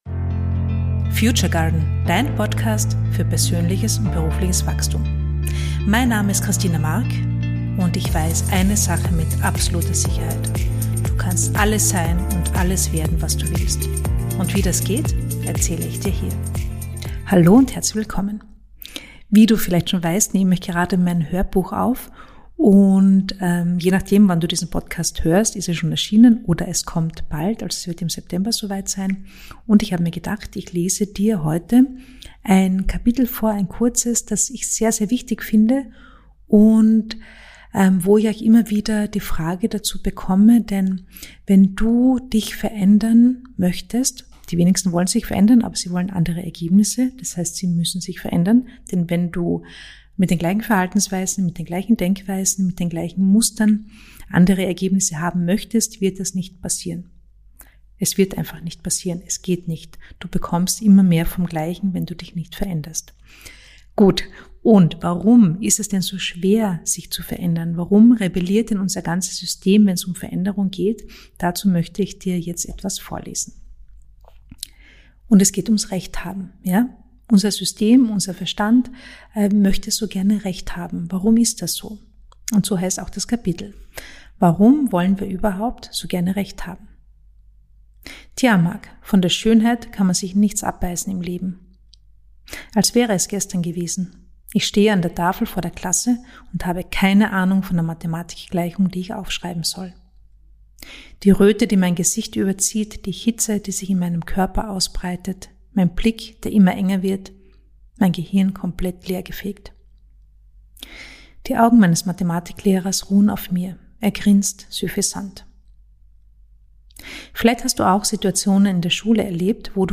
Ich lese dir einen Abschnitt aus meinem Buch "Chef*in im eigenen Kopf" vor, in dem es darum geht, was deine Veränderung blockieren kann und was du tun kannst, um sie in Fahrt zu bringen oder überhaupt erst zu ermöglichen.